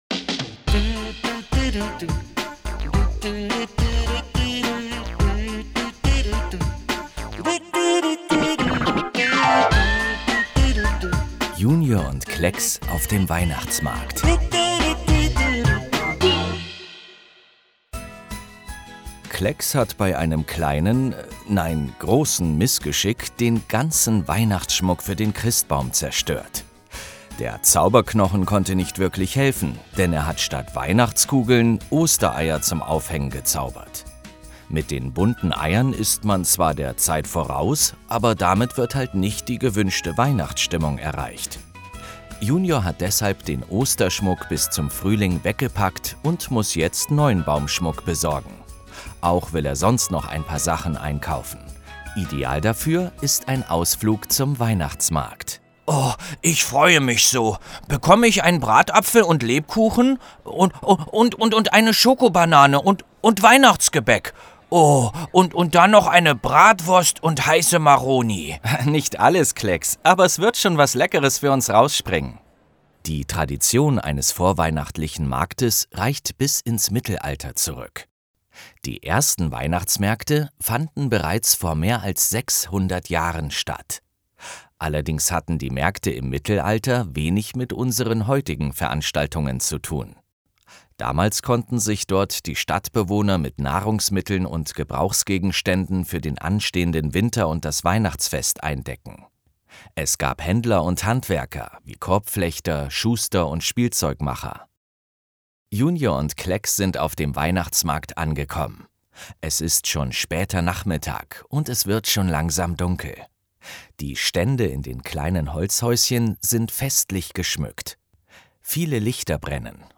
24 12 Hörspiel - JUNIOR Schweiz